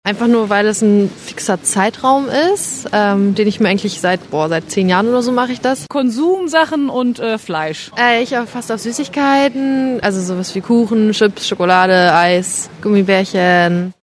RADIO RST-Hörer über das Fasten